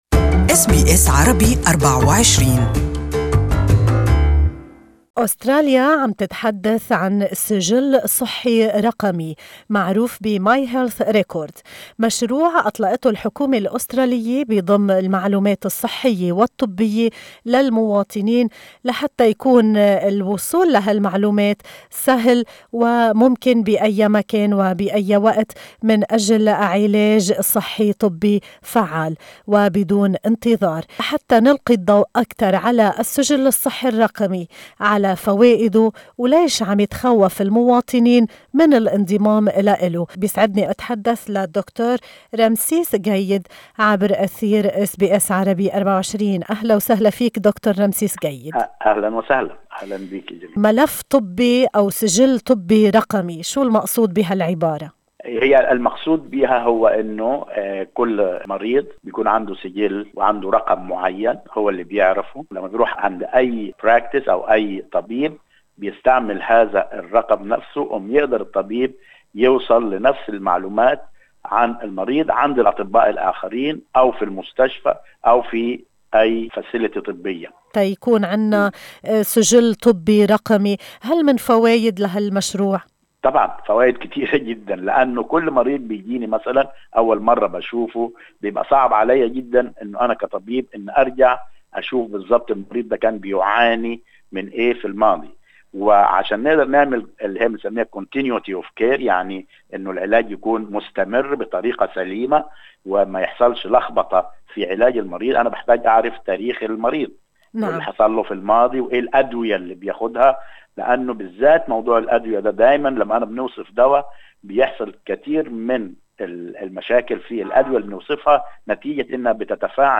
المزيد في المقابلة الصوتية المرفقة بالصورة.